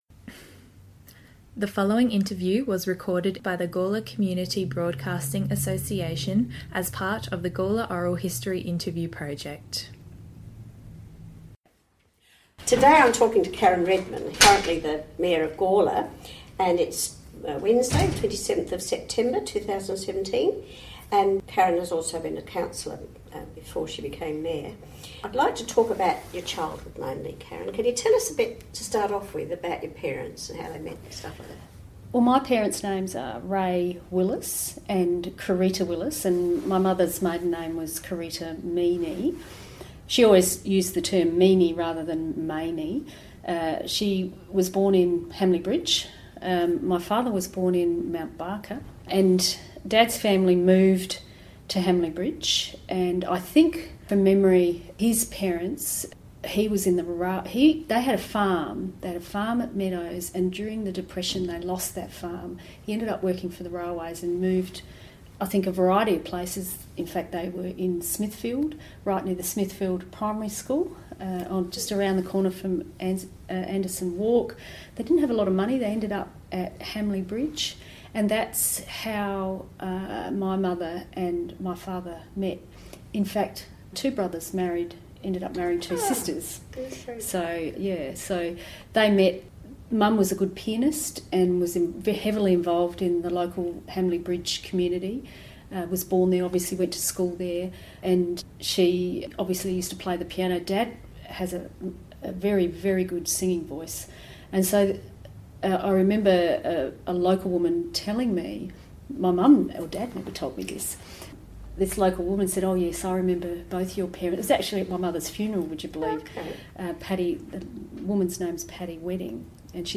She shares with humour and pathos an important time in her life.